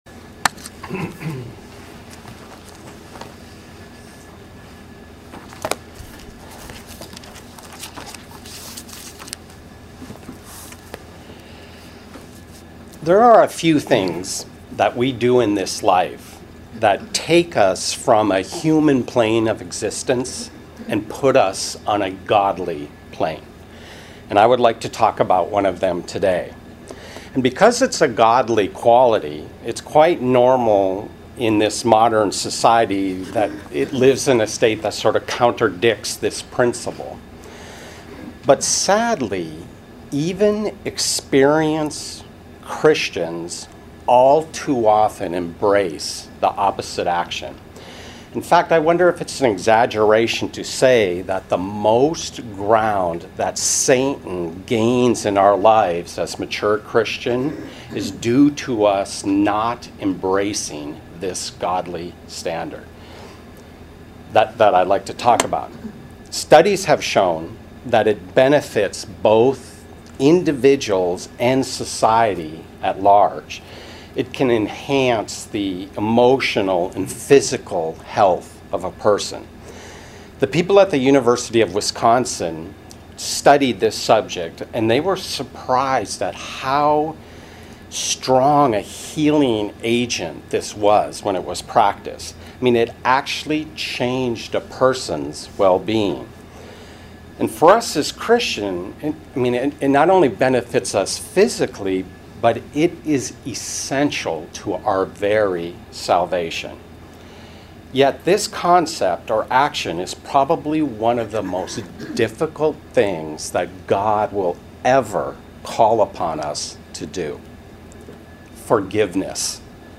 Sermons
Given in Hartford, CT